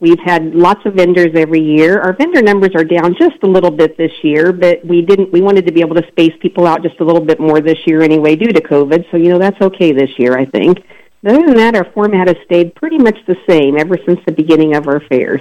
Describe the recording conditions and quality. On KVOE’s Morning Show Tuesday